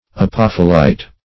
apophyllite - definition of apophyllite - synonyms, pronunciation, spelling from Free Dictionary
Search Result for " apophyllite" : The Collaborative International Dictionary of English v.0.48: Apophyllite \A*poph"yl*lite\, n. [Pref. apo- + Gr.